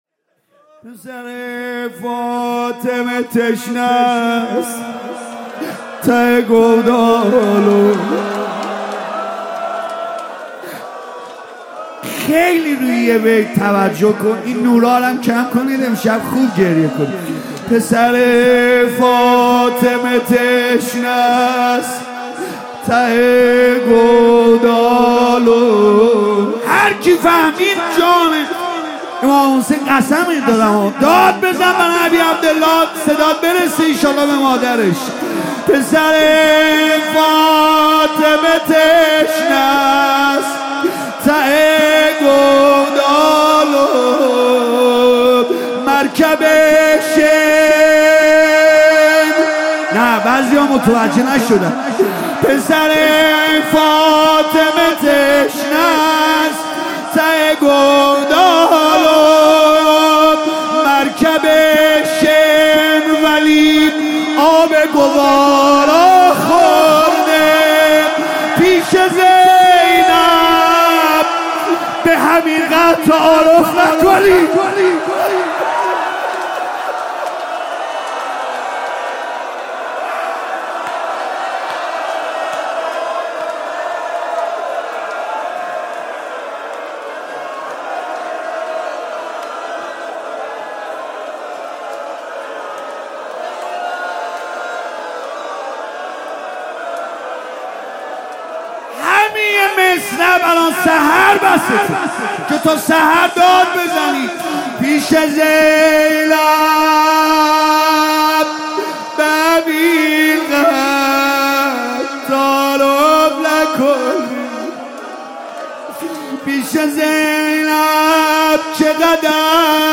مداحی جدید
مراسم هفتگی هیات فدائیان حسین (ع) اصفهان